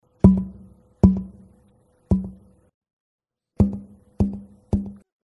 These are audio clips from the 2011 convention workshop.
These two Sitka tops have little lateral stiffness, and can practically be bent into a tube. Relatively articulate, but with little sustain.